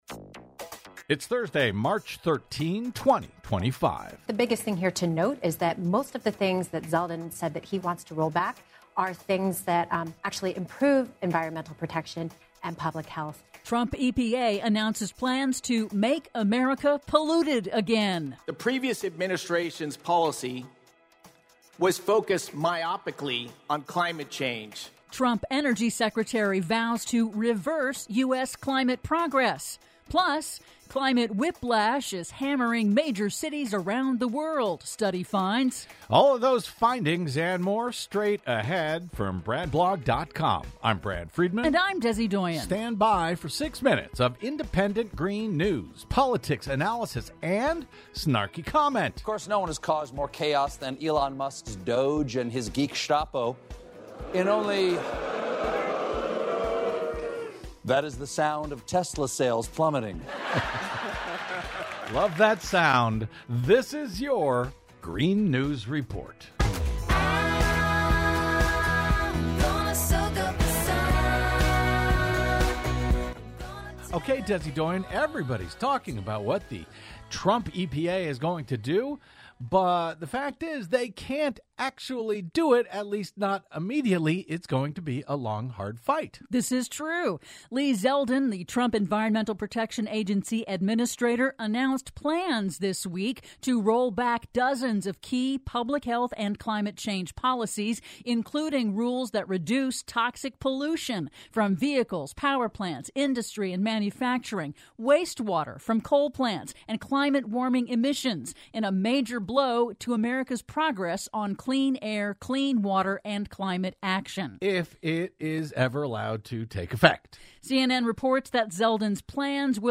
IN TODAY'S RADIO REPORT: Trump EPA announces plans to Make America Polluted Again; Trump Energy Secretary vows to reverse U.S. climate progress; PLUS: Climate whiplash is hammering major cities around the world, study finds...